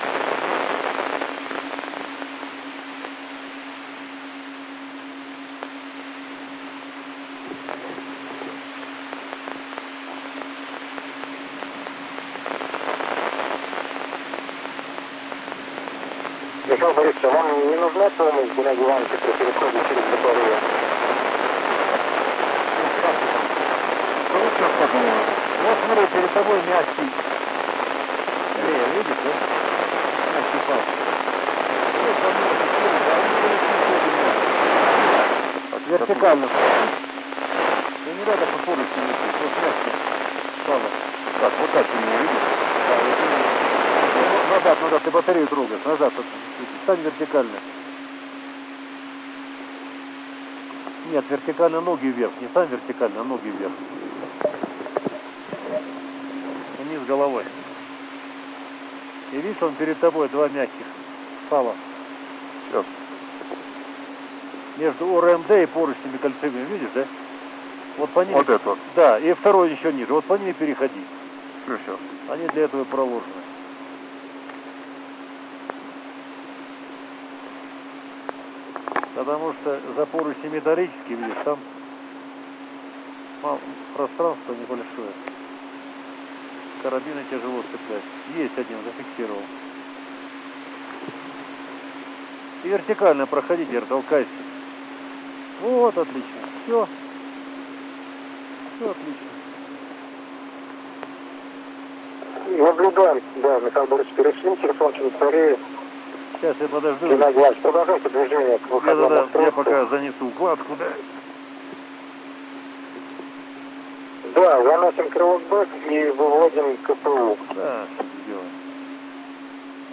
Downlink from EVA-44 on ISS August 10 2015